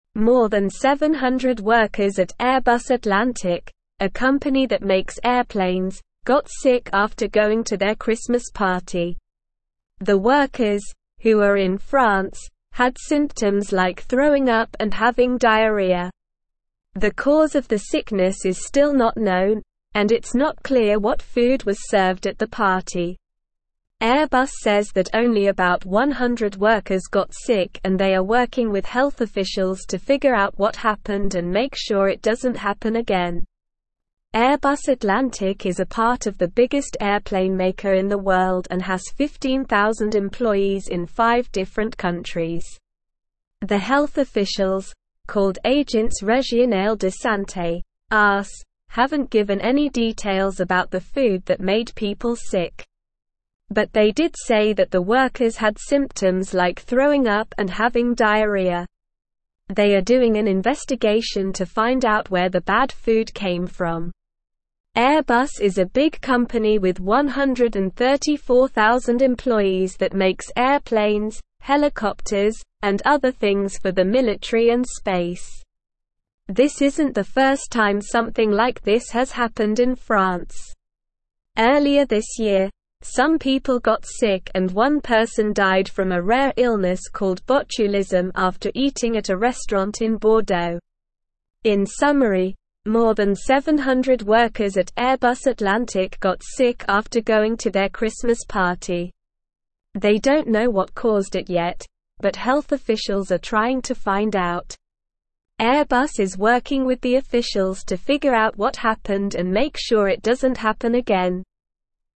Slow
English-Newsroom-Upper-Intermediate-SLOW-Reading-Over-700-Airbus-staff-fall-ill-after-Christmas-dinner.mp3